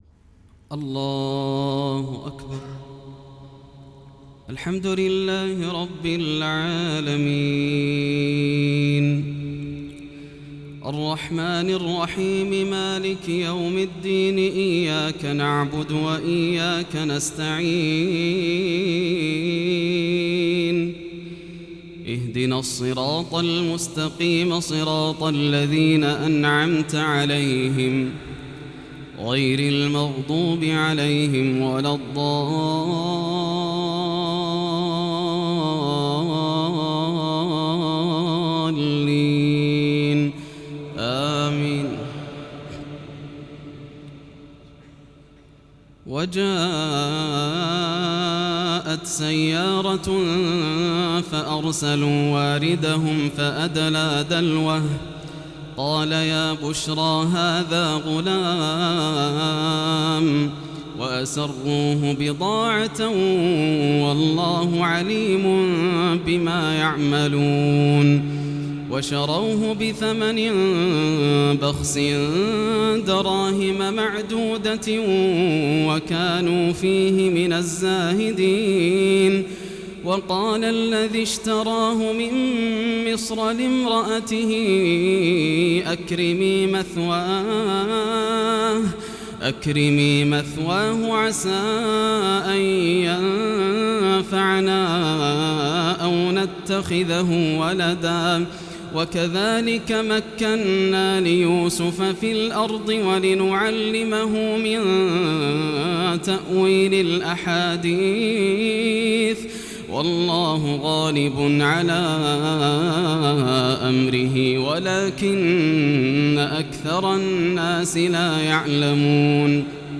تراويح ليلة 12 رمضان 1432هـ من مسجد جابر العلي في دولة الكويت > الليالي الكاملة > رمضان 1432 هـ > التراويح - تلاوات ياسر الدوسري